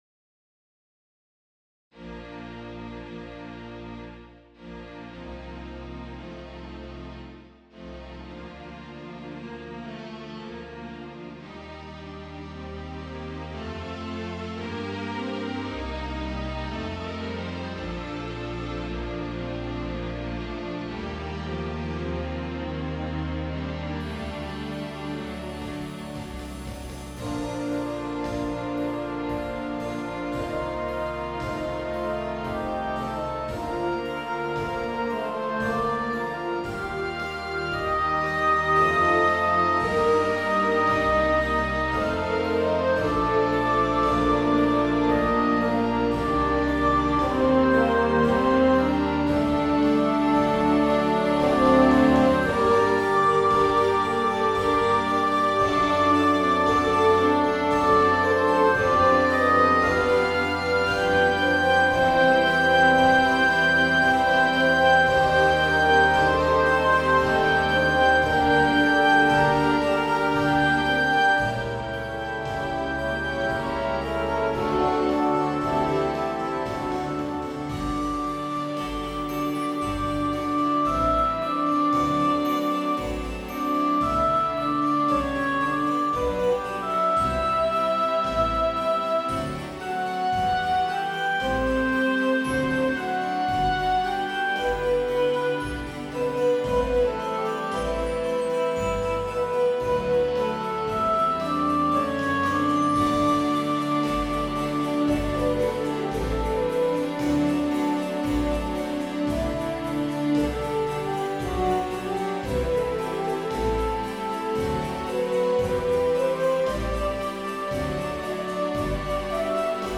Scores for symphony orchestra